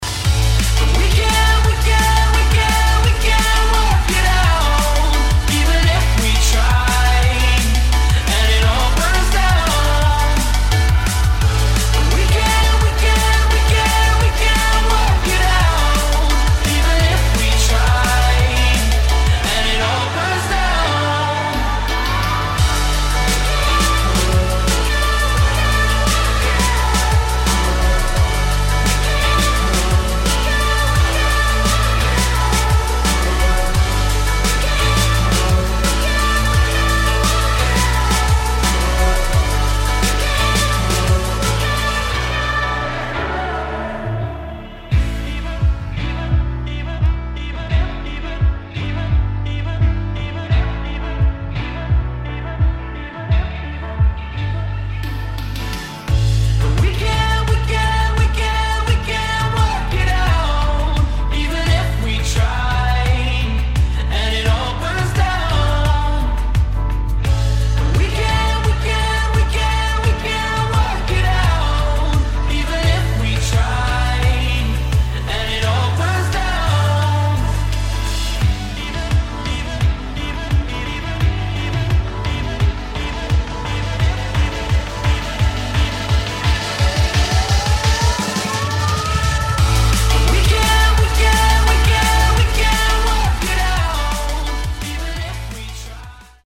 [ DRUM'N'BASS / POP / DUBSTEP ]